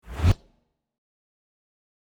دانلود آهنگ دعوا 6 از افکت صوتی انسان و موجودات زنده
جلوه های صوتی
دانلود صدای دعوای 6 از ساعد نیوز با لینک مستقیم و کیفیت بالا